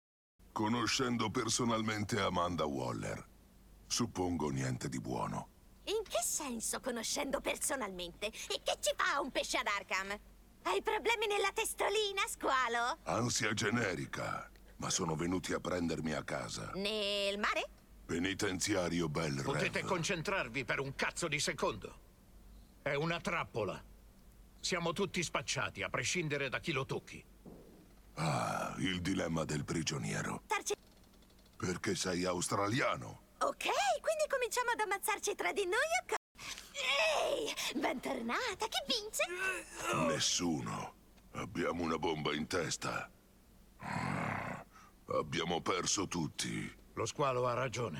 nel videogame "Suicide Squad: Kill The Justice League", in cui doppia il personaggio King Shark.